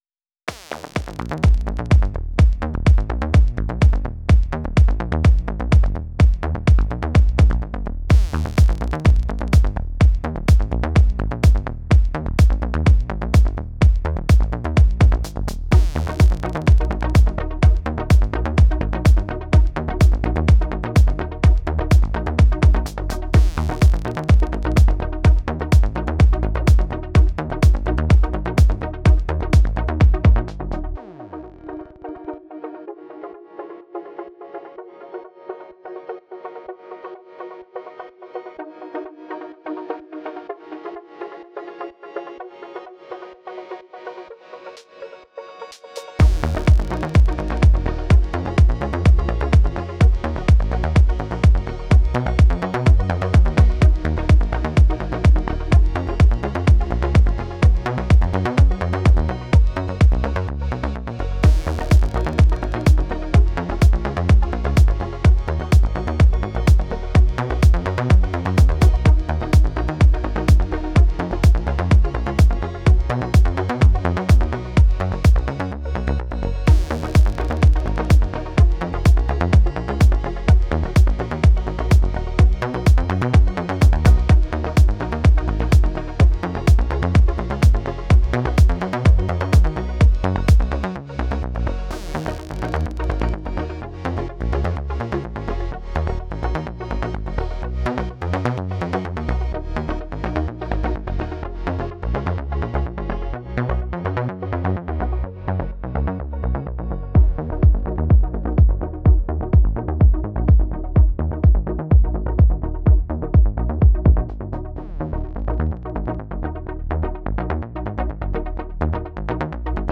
ELECTRO G-N (38)